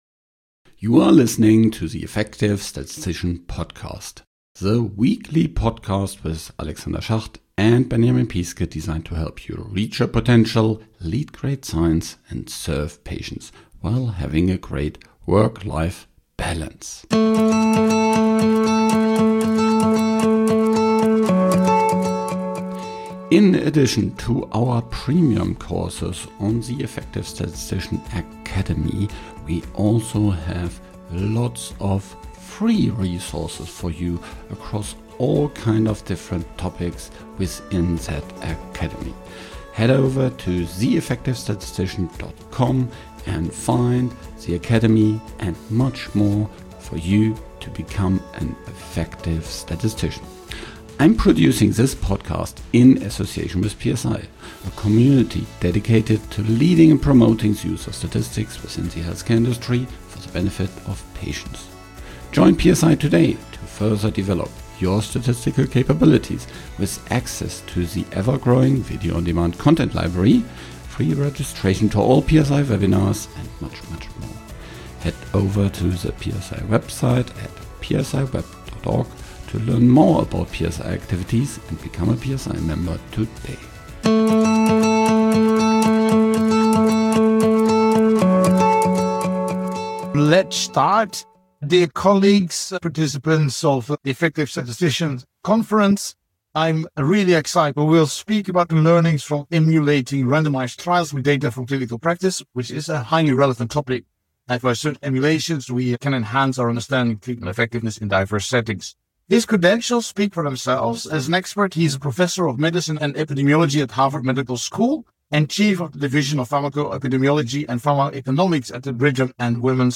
Recorded live at The Effective Statistician Conference 2024, this talk explores whether non-randomized studies based on electronic health records and claims data can reach conclusions as reliable as those from traditional RCTs.